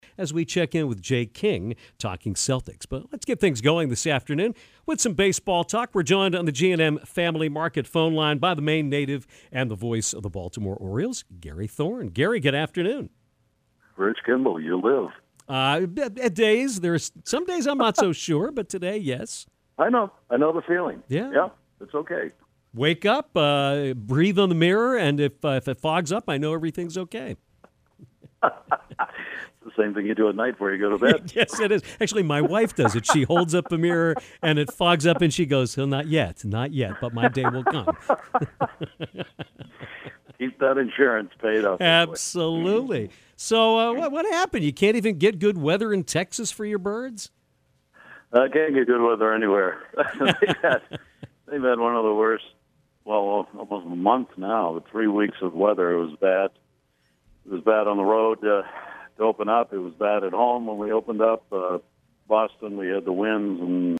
Maine native Gary Thorne, currently the voice of the Baltimore Orioles, called into Downtown to give us an in-depth look at this season’s incarnation of the O’s. He talked about Mark Trumbo being a surprise, Manny Machado sometimes getting overlooked among the top tier of players, the tightness of the AL East top-to-bottom, the health of the game itself, and how people will view Rafael Palmiero and other steroid era players over time versus the players who did things the right way.